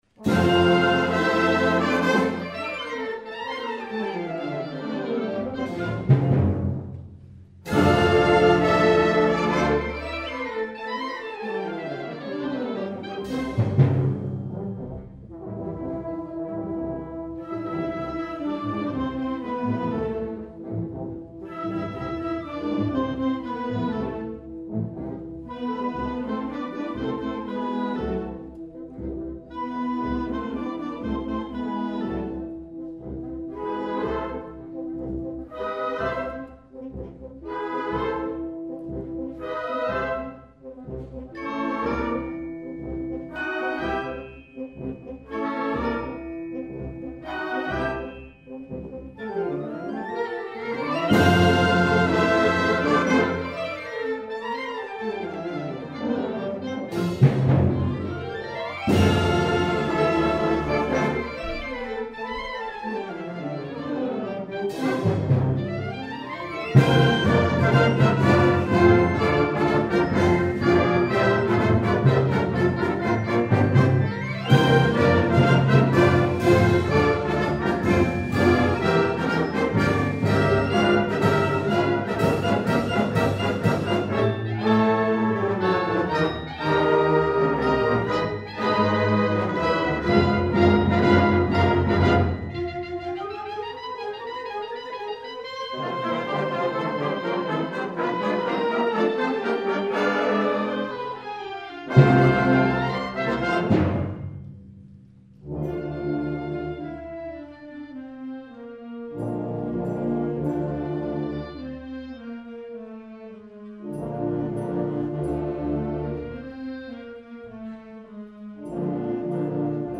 2009 Winter Concert
FLUTE
CLARINET
TRUMPET
PERCUSSION